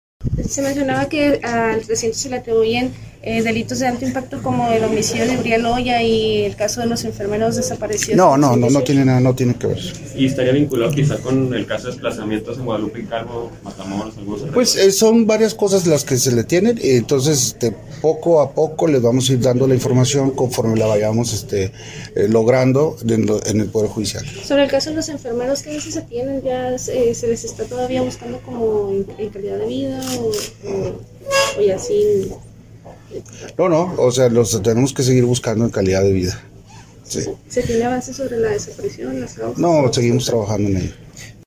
A continuación las declaraciones: